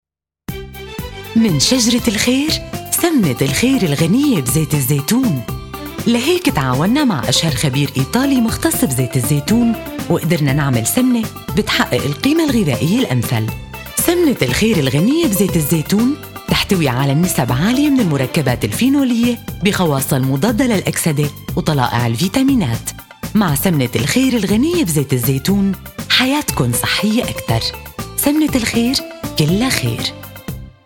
Voice Description: Real Perky Warm Sexy Young Mom Energetic Attitude Articulate Smart IN ONE WORD : ( Professional )
Arabic female voice over, UAE voice over, Professional female voiceover artist, voice over talent, Arabic voice over
Sprechprobe: Industrie (Muttersprache):